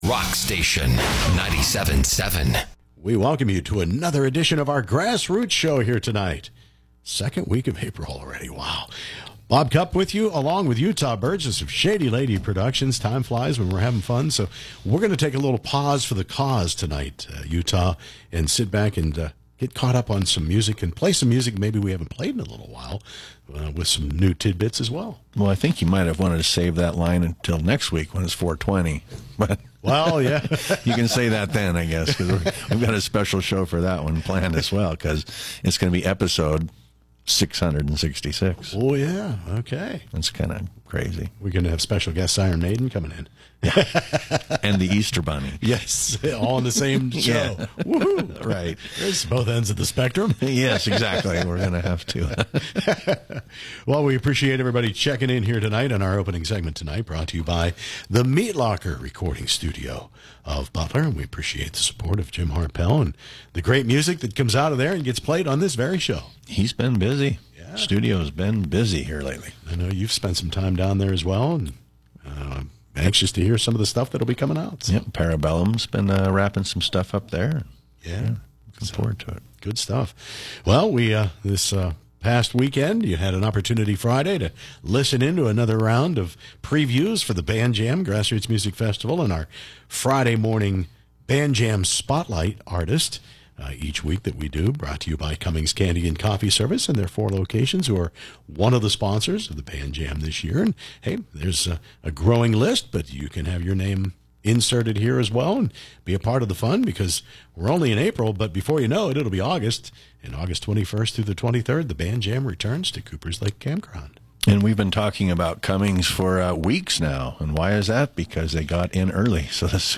Tonight is one of our music and discussion shows. We’ll talk The Band Jam Grass Roots Music Festival, the scene, and who knows what else!